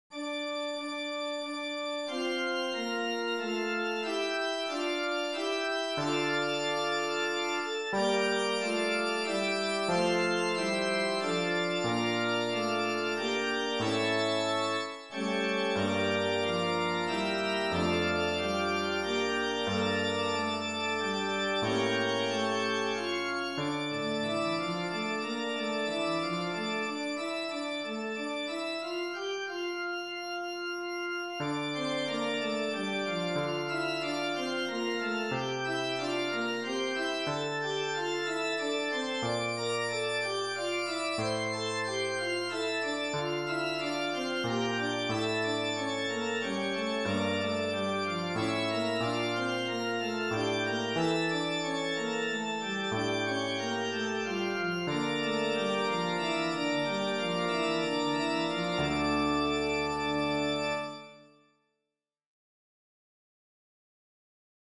It is appropriate as prelude or an organ solo.